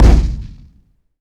weapon_cannon_shot_01.wav